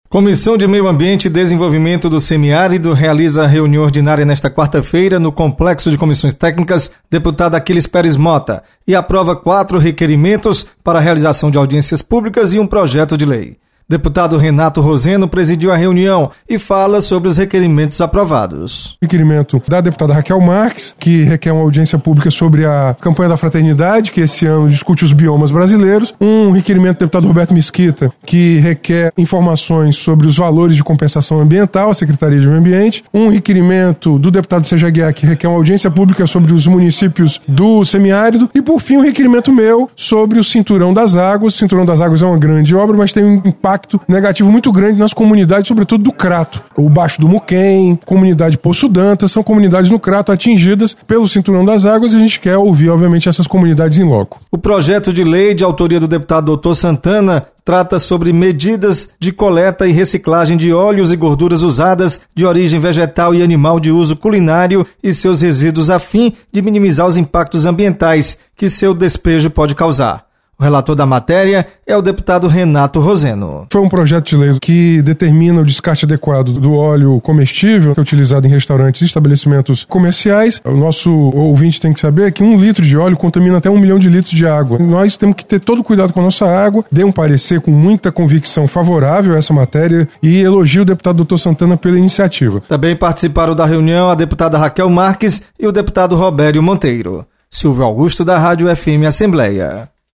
Comissão do Meio Ambiente e Desenvolvimento do Semiárido realiza reunião nesta quarta-feira. Repórter